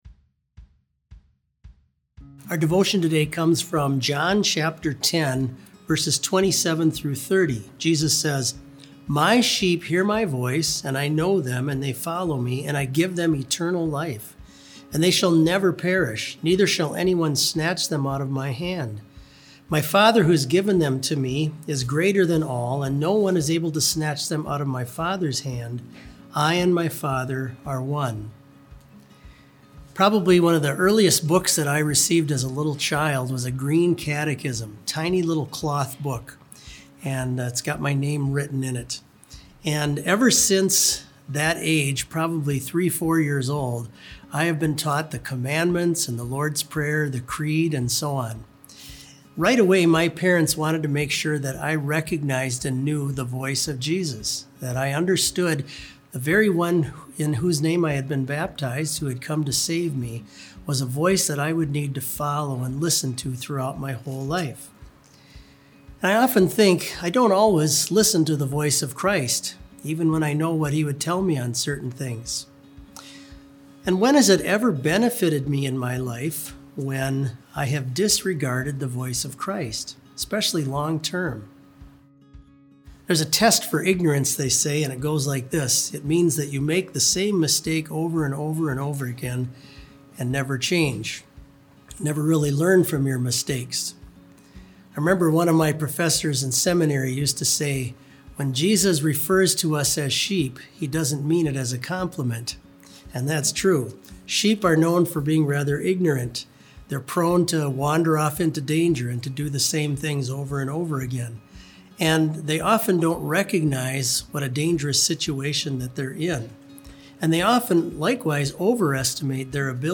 Complete service audio for BLC Devotion - April 24, 2020